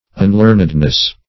-- Un*learn"ed*ness , n. [1913 Webster]